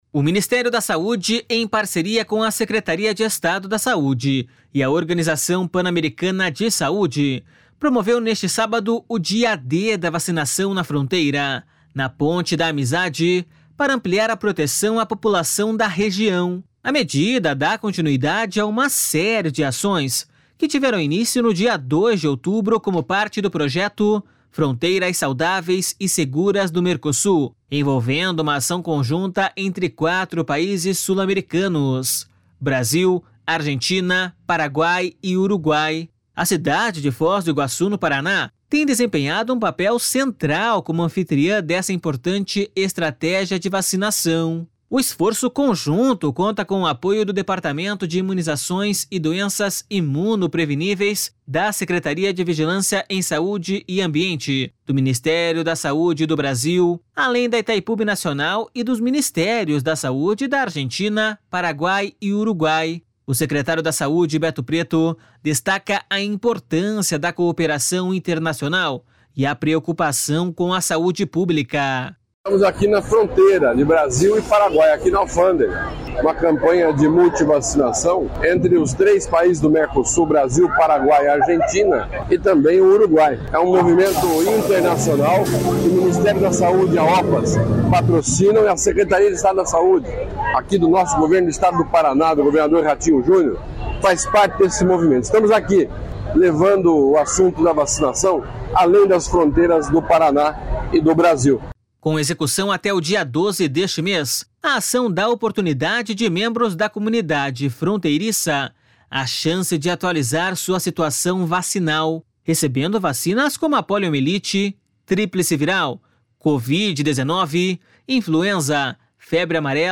O secretário da Saúde, Beto Preto, destaca a importância da cooperação internacional e a preocupação com a saúde pública.// SONORA BETO PRETO.//